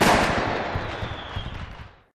firework_explosion_01.ogg